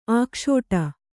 ♪ ākṣōṭa